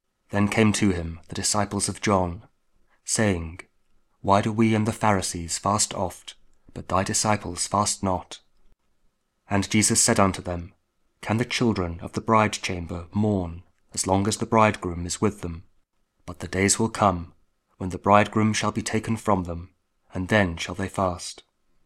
Matthew 9: 14-15 – Friday after Ash Wednesday (King James Audio Bible, Spoken Word)